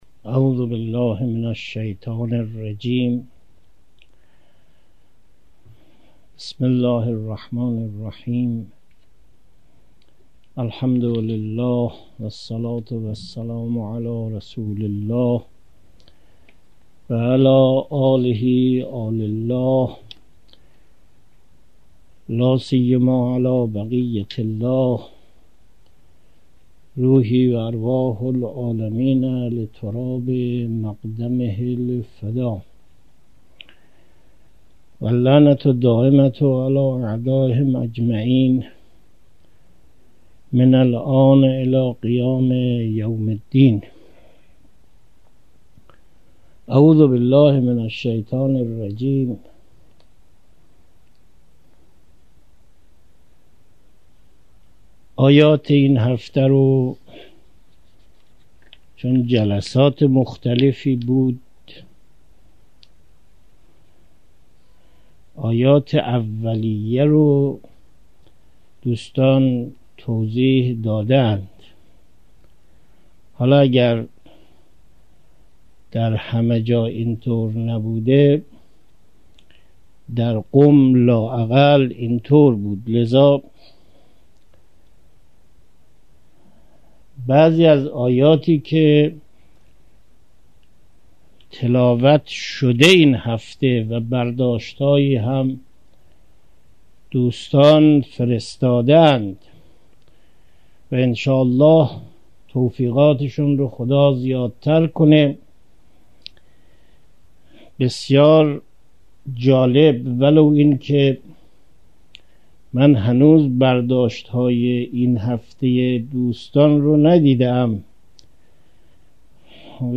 درس دوازدهم توضیح آیات